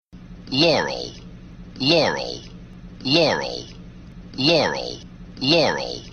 This sound could be described as an "audio illusion". Some people hear "laurel", but others hear "yanny".
In that audio clip, the "yanny" sound is at a high pitch, and it seems to be at a low volume level, whereas "laurel" seems to cover the entire frequency range, and it seems to be at a higher volume level.